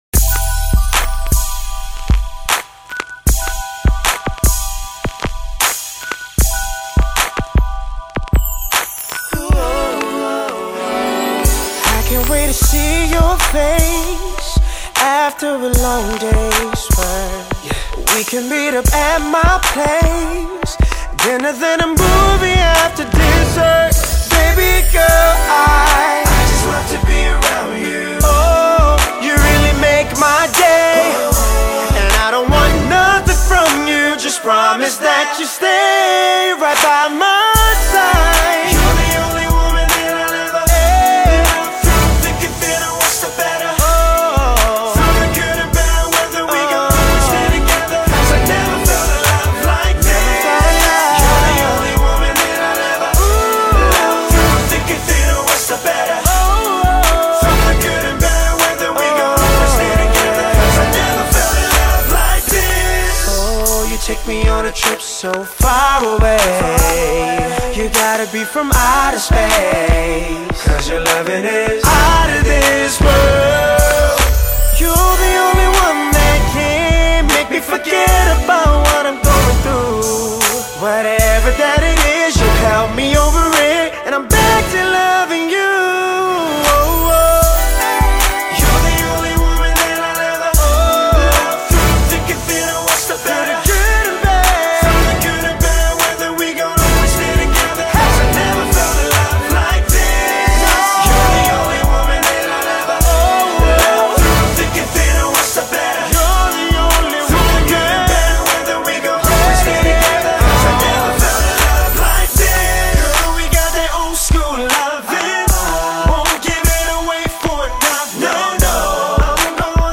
The stellar crooner drops yet another single from the debut
infectiously sultry